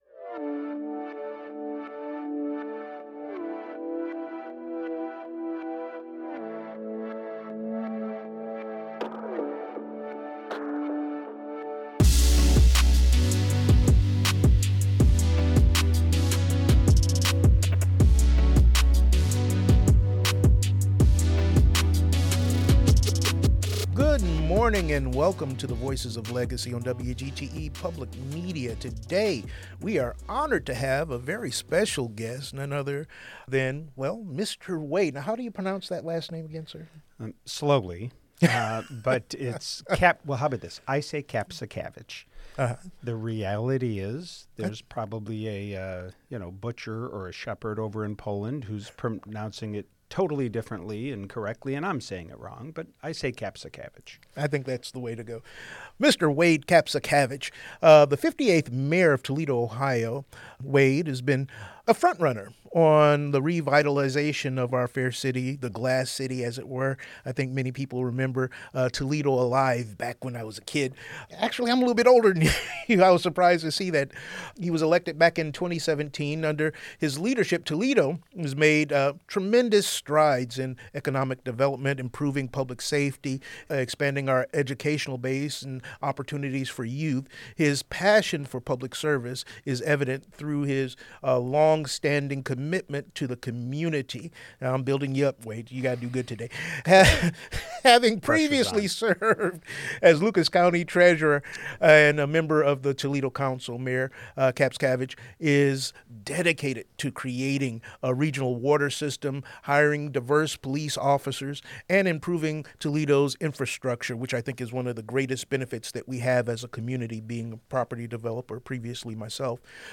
Join us for an insightful conversation with Mayor Wade Kapszukiewicz, the dynamic leader of Toledo, Ohio. Since taking office, Mayor Kapszukiewicz has focused on revitalizing the city through economic development, community engagement, and sustainable initiatives.